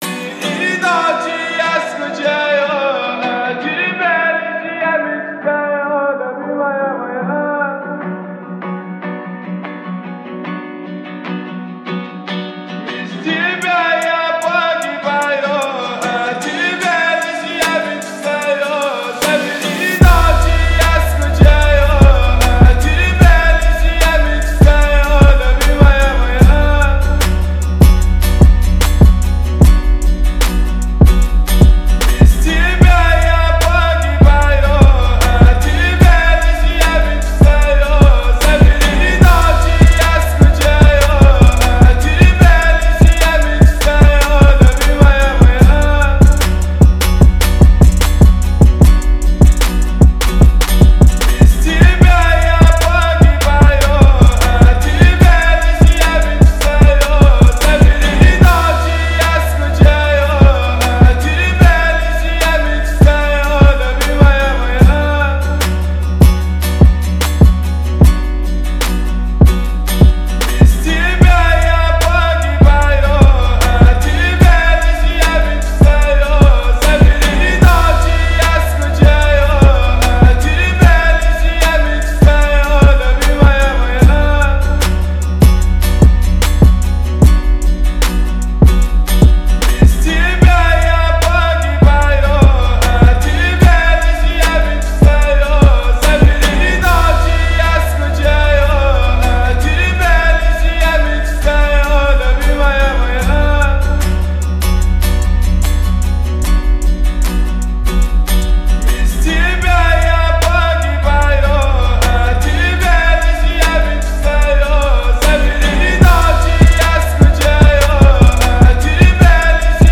это трек в жанре поп с элементами R&B